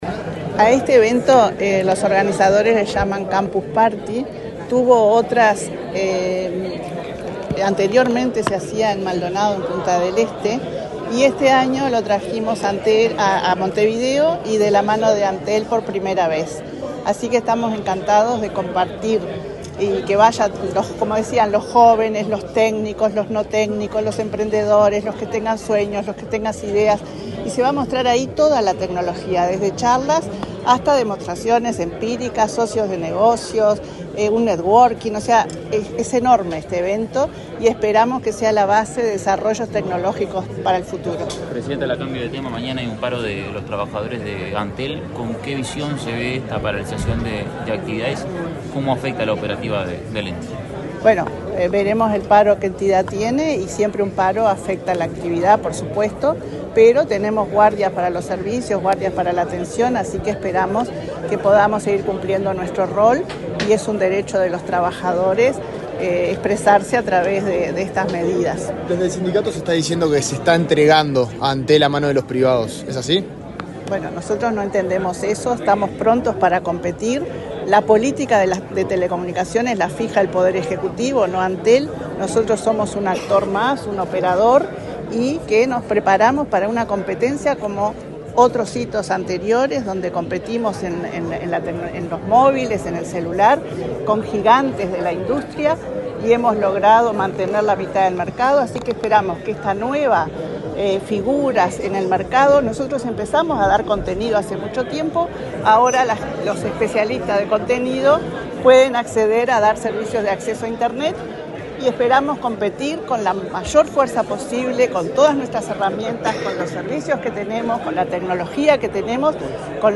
Declaraciones de la presidenta de Antel, Annabela Suburú
Luego, dialogó con la prensa.